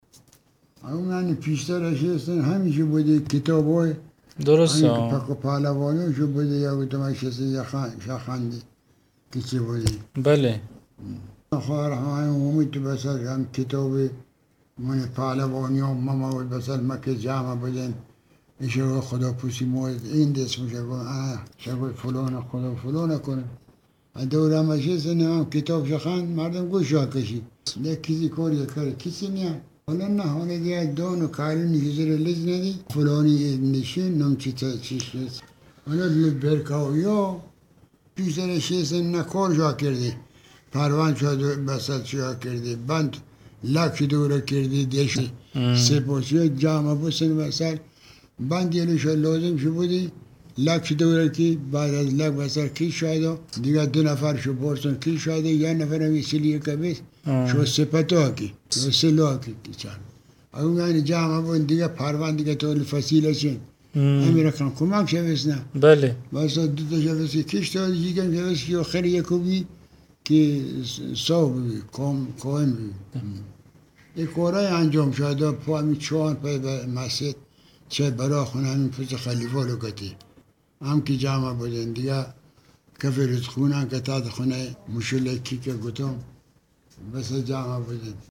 پای صحبت های پیران و بازماندگان قدیمی شهر که می نشینی، انبوهی از خاطرات تلخ و شیرین گذشته برایت نمایان می شود.